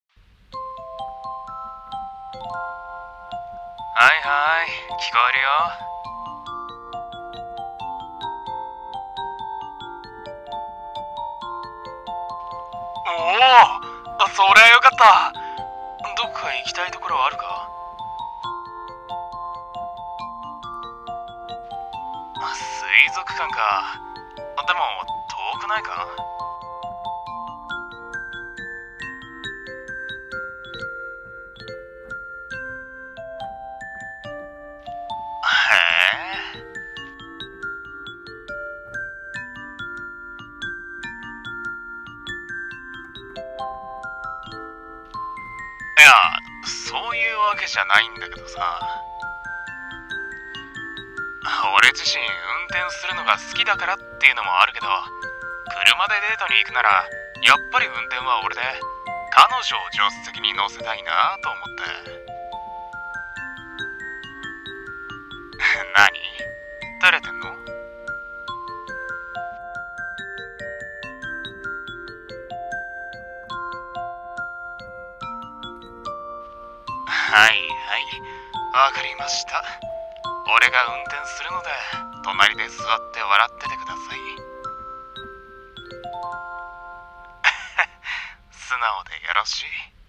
コラボ募集【カップル声劇】デートの約束